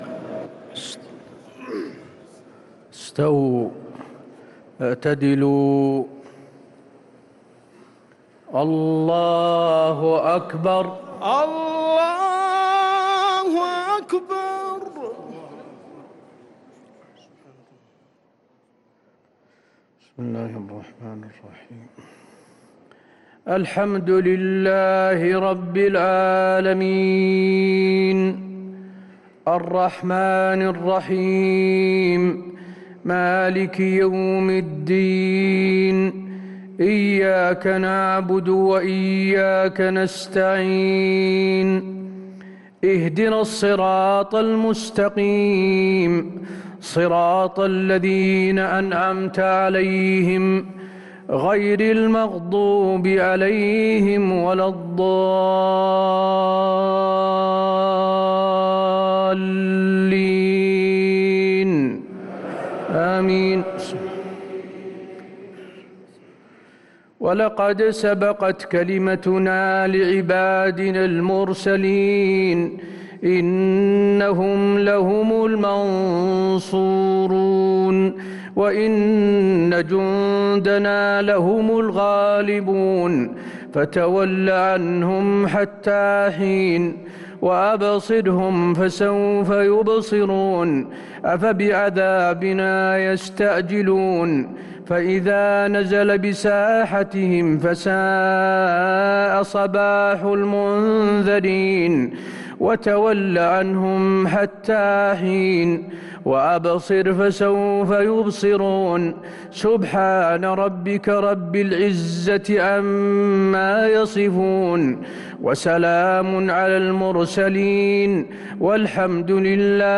صلاة العشاء للقارئ حسين آل الشيخ 12 رمضان 1444 هـ
تِلَاوَات الْحَرَمَيْن .